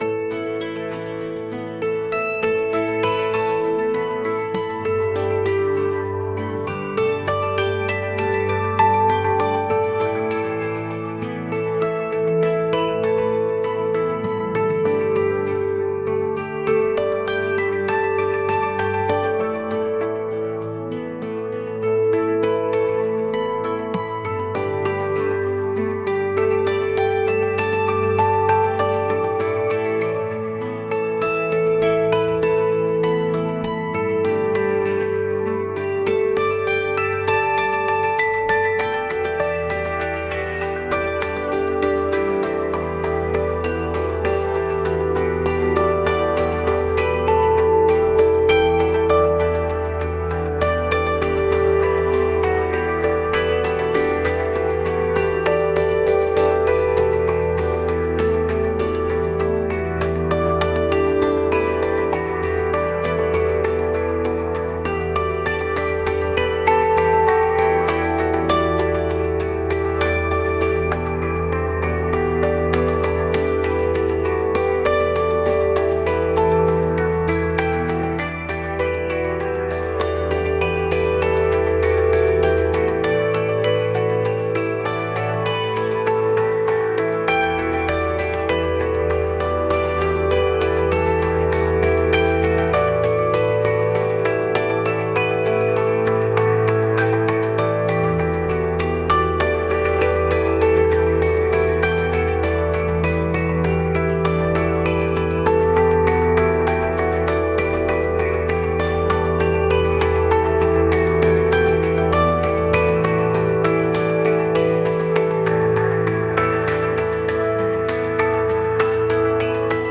おしゃれ